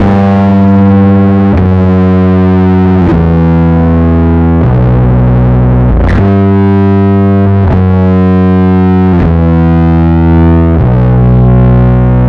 Track 07 - Bass 03.wav